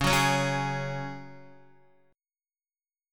Dbsus4 chord